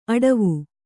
♪ aḍavu